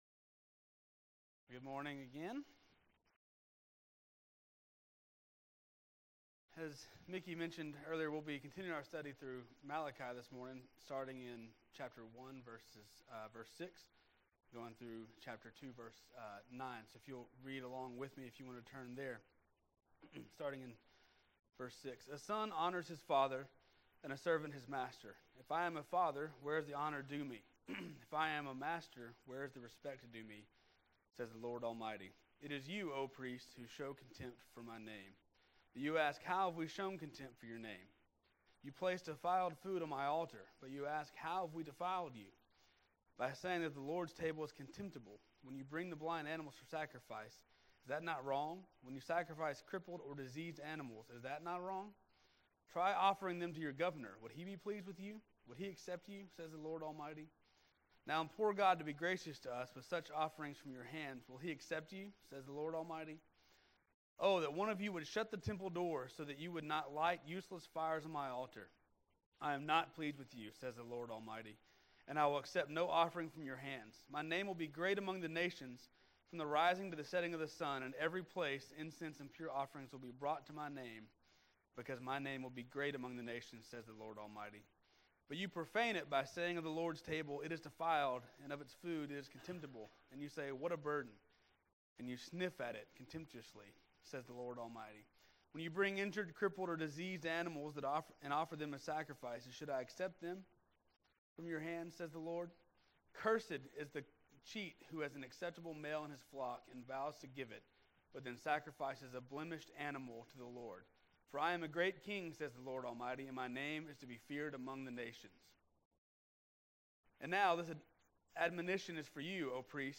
May 29, 2016 Morning Worship | Vine Street Baptist Church